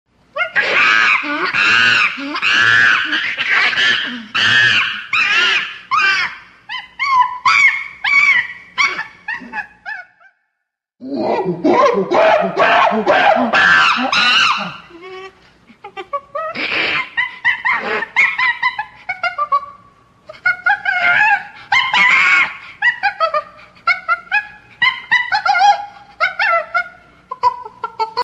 Monkey Scream Sound Effect Download: Instant Soundboard Button
Monkey Scream Sound Button - Free Download & Play